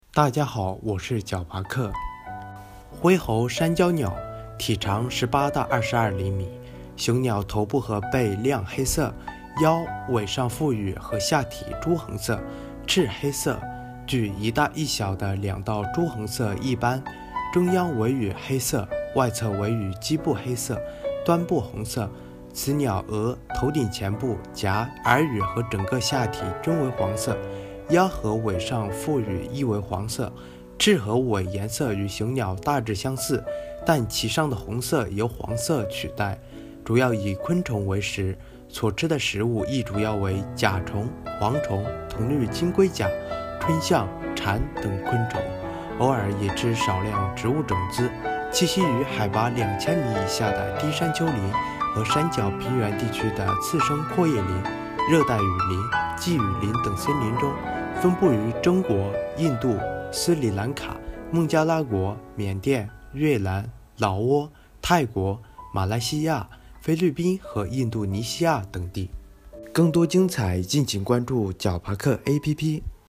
灰喉山椒鸟-----呼呼呼～～～